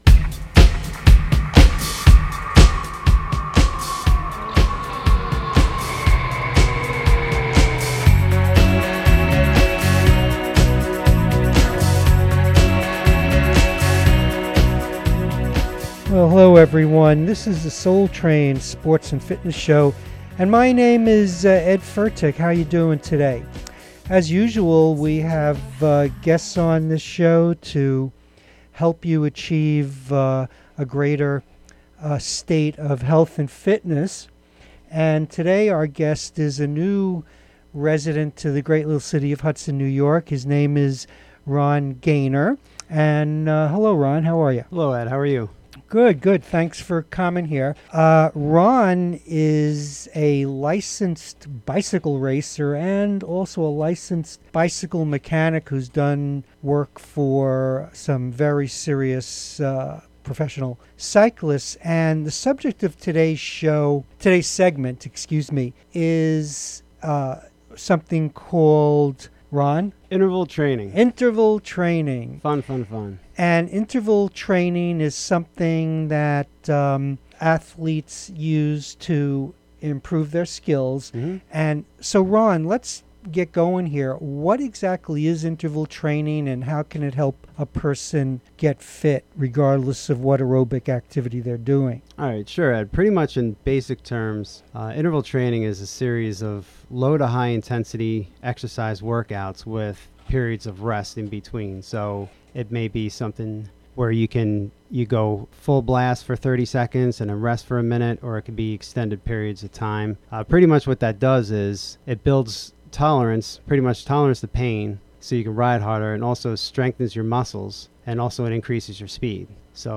Health and fitness segment